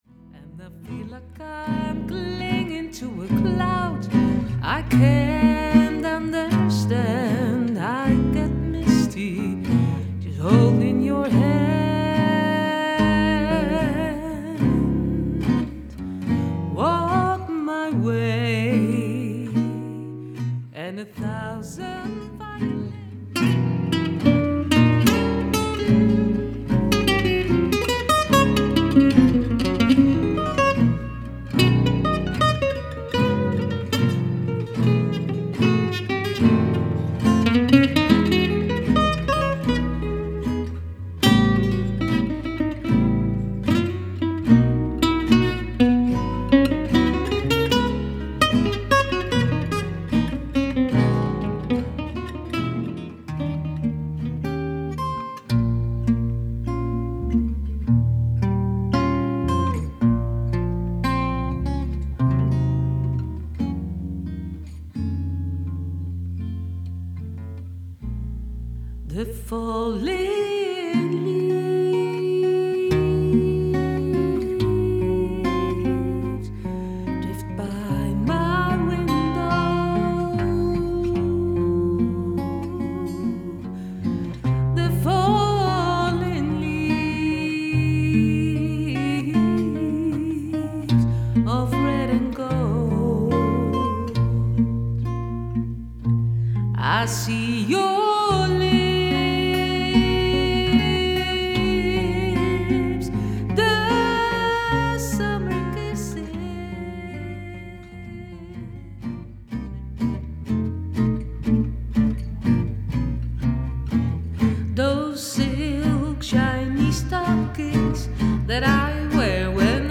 Puur samenspel
Sfeervol, intiem en volledig in eigen hand.